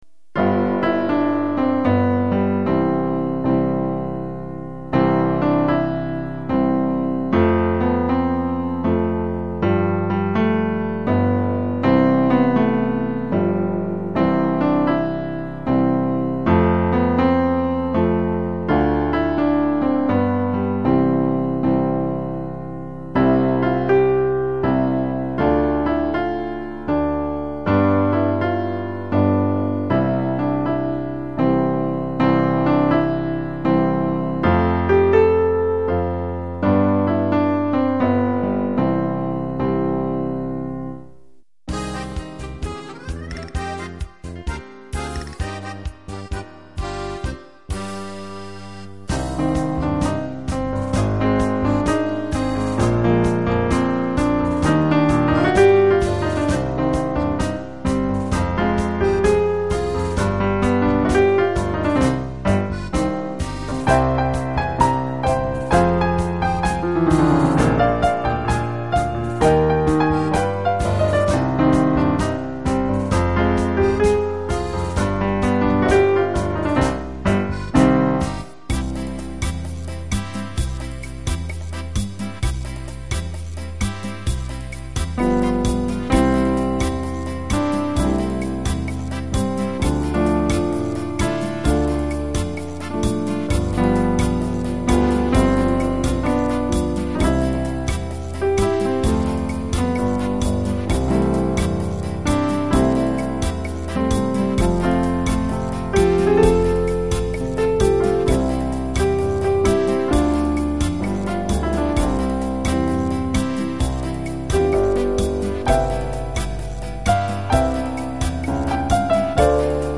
Yhdellä otoksella nauhoitettua pianoimprovisointia